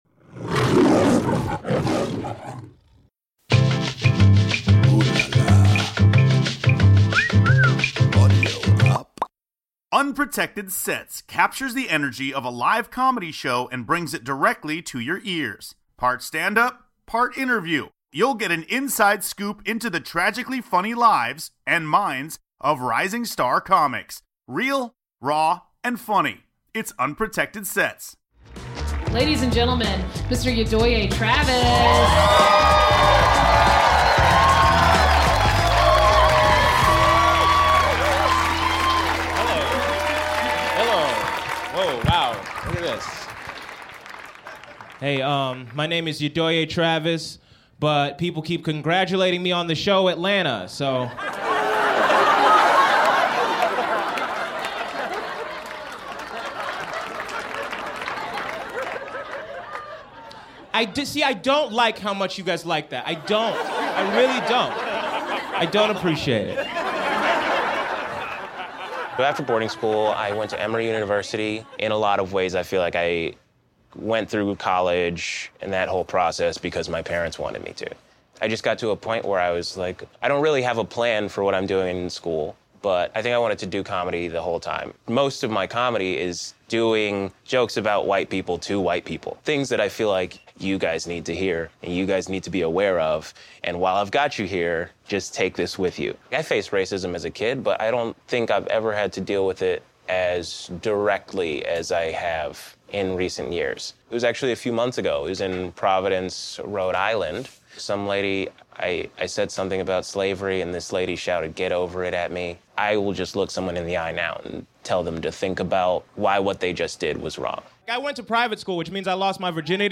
Unlike any other stand up series or special, the show will present compelling portraits of emerging comedians as they work the comedy circuit. Meet each comedian and hear intimate interviews, chronicling how they turned their personal paths… into hysterical laughs.